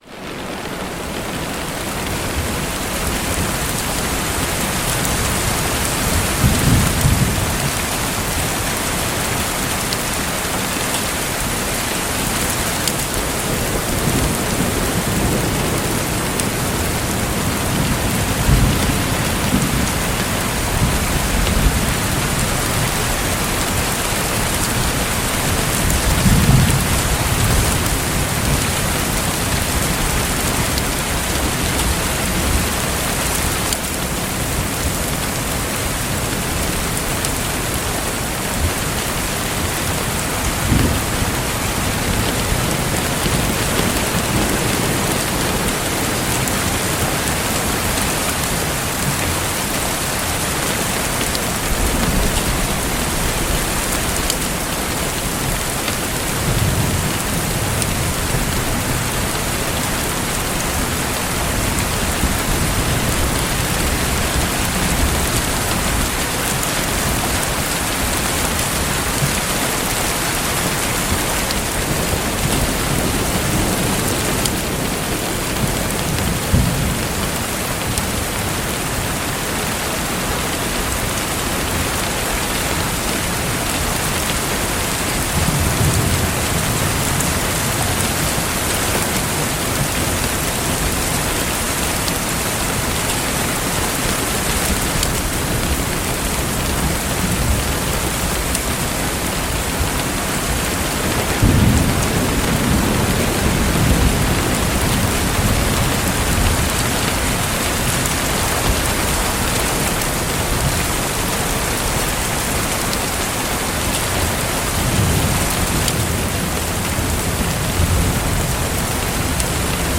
(Ads may play before the episode begins.)The sky opens with a low growl of thunder.